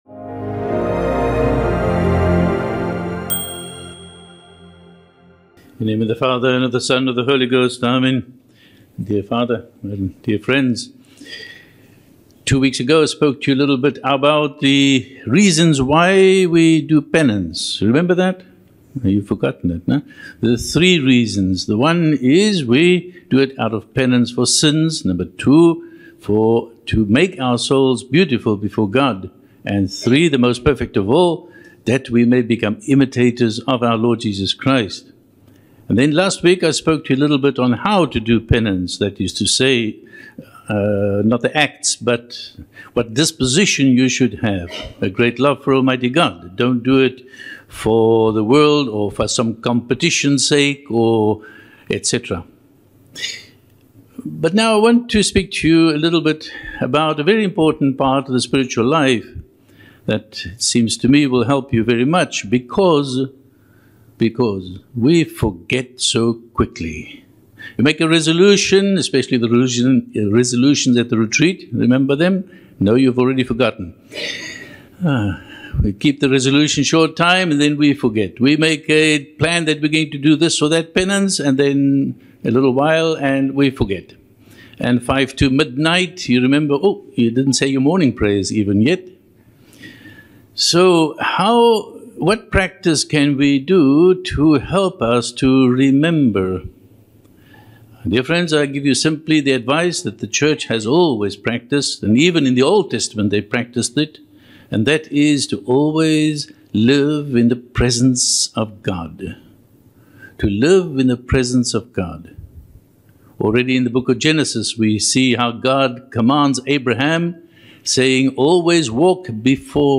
The Presence of God - SSPX Sermons